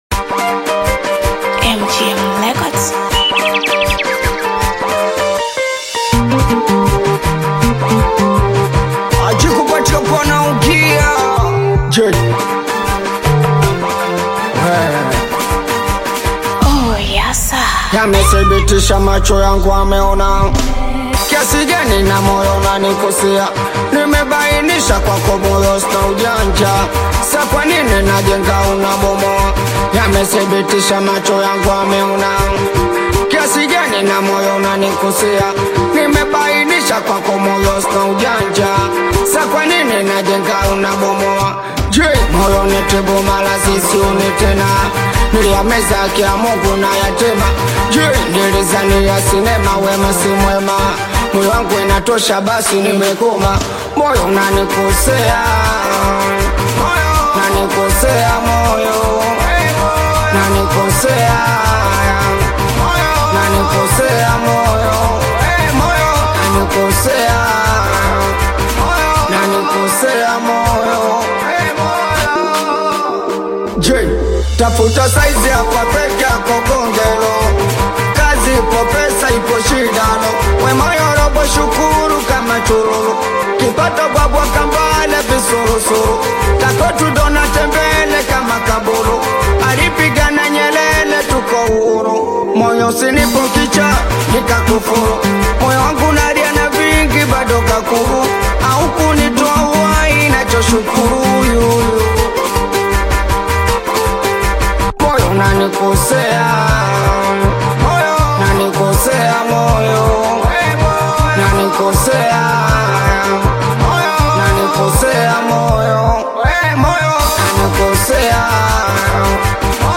Tanzanian Singeli/Afro-Pop single